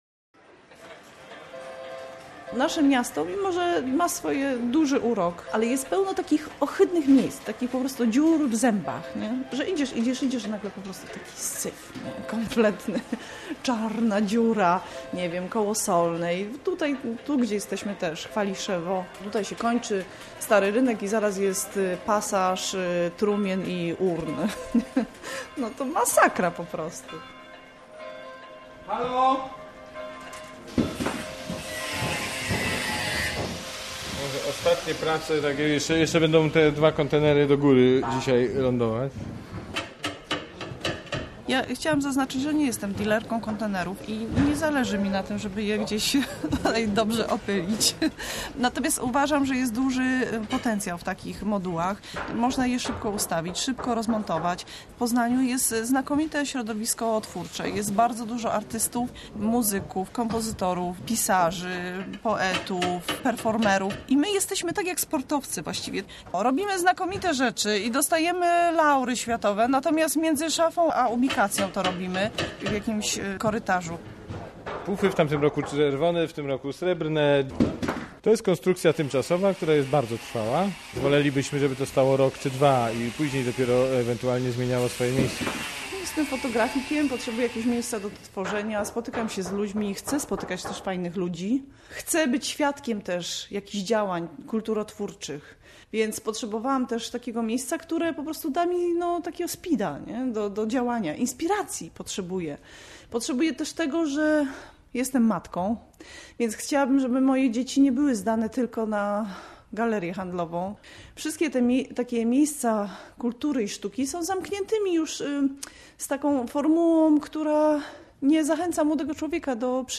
Reportaż - 20 sierpnia 2011 - Radio Poznań